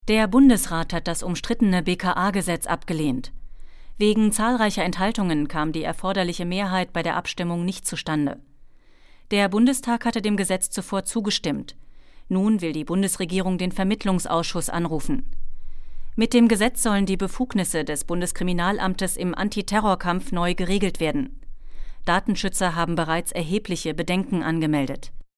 Sprechprobe: Sonstiges (Muttersprache):
german female voice over talent